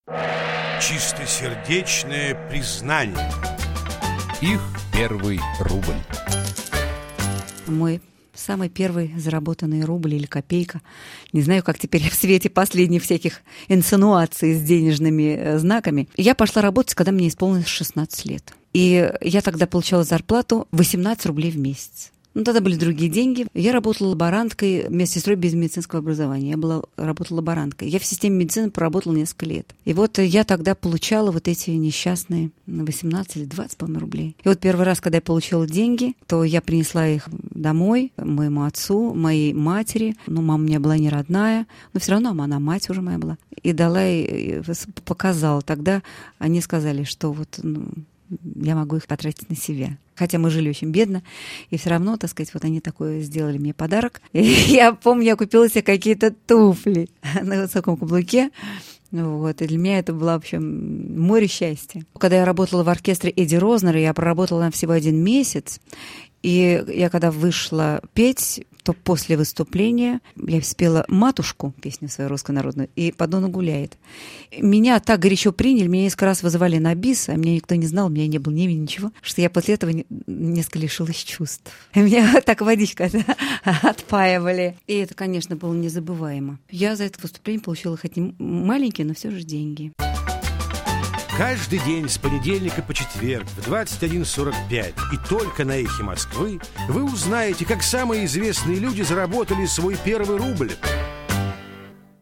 ЖАННА БИЧЕВСКАЯ певица, народная артистка России - Мой первый рубль - 2000-03-07